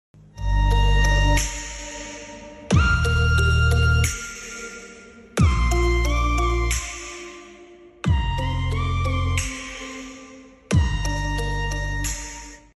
Part 87｜YEAHBOX speaker Sonic Boom